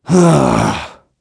Clause_ice-Vox-Deny.wav